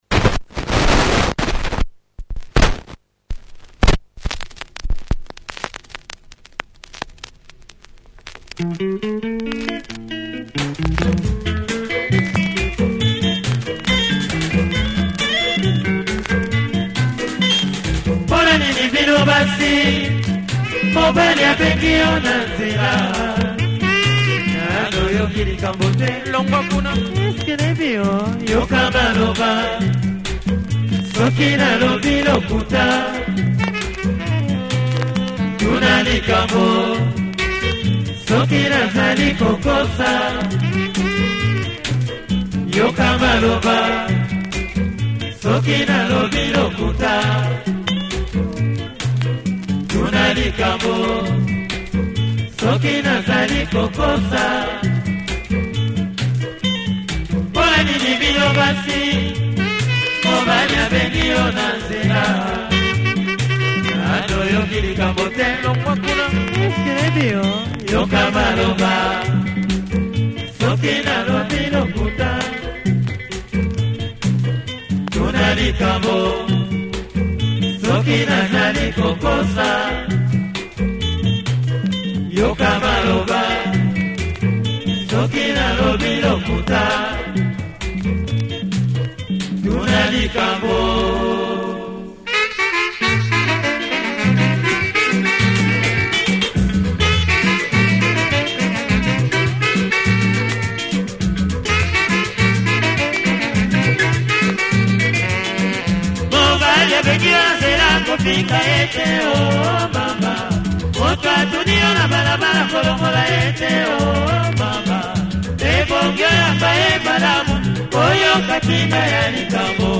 In rumba mode.